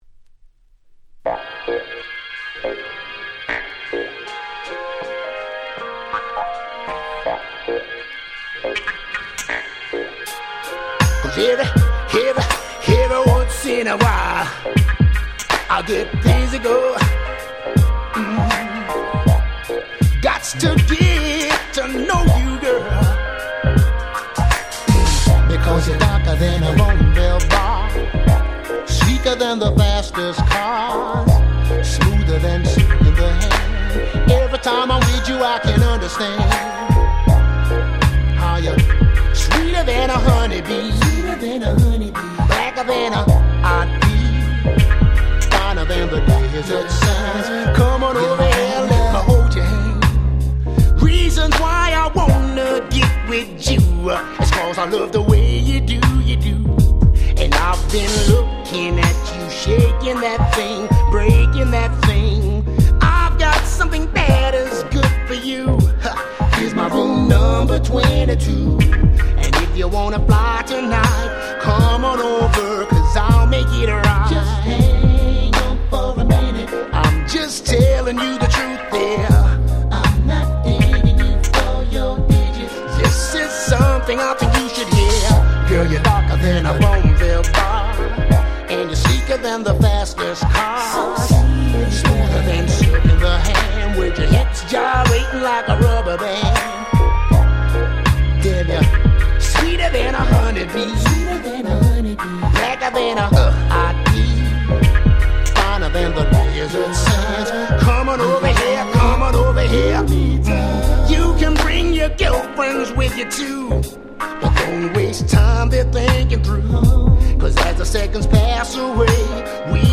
01' Very Nice R&B !!
詳細不明の良質マイナー/インディーR&B !!
本当に全く詳細不明ですがめちゃGroovyで最高！！
00's Indie R&B